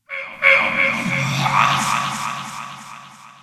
PixelPerfectionCE/assets/minecraft/sounds/mob/wither/hurt3.ogg at mc116
hurt3.ogg